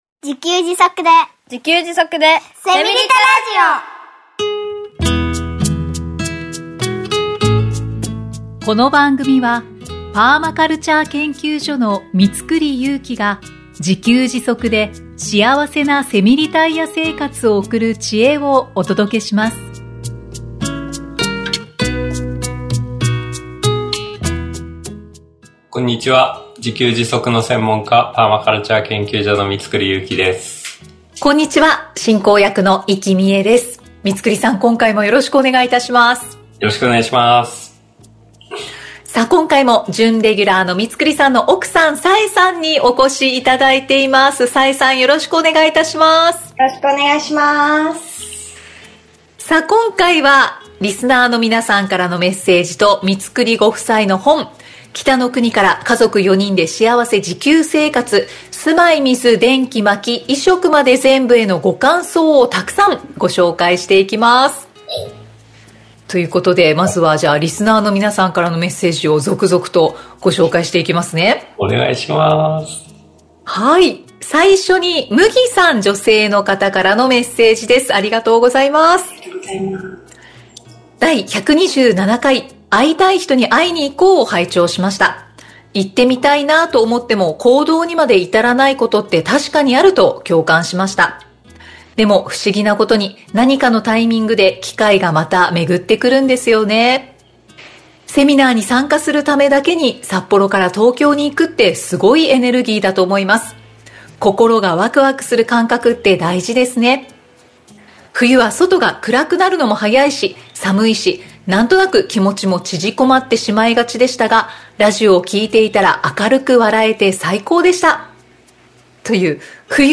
3人でお送りしています。 リスナーの皆さんからのメッセージと、お寄せいただいた本のご感想をご紹介しています。